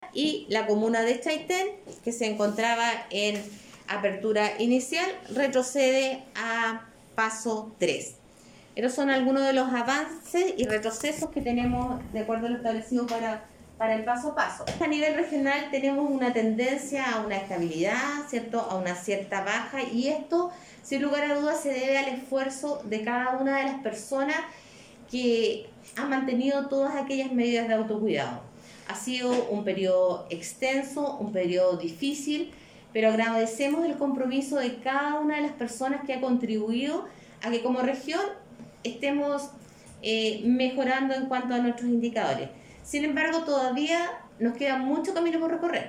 Así lo señaló la Seremi de Salud (s) de Los Lagos, Marcela Cárdenas, quien además analizó el estado de la región y esta leve disminución de casos.